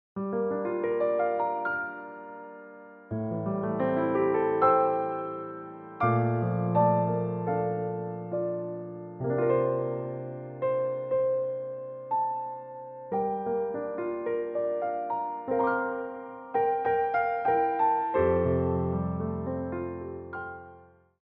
Port de Bras
4/4 (8x8)